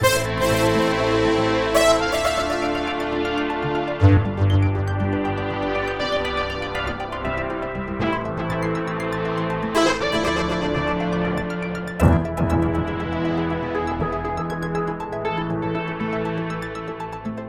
Space Requiem (melodic experimental)
рекомендую слушать только в наушниках...
короткая, почти чистая, без наложений разных вариантов (только немного дилея на средних и высоких и немного гудизера D ) здесь в моно выложил вариант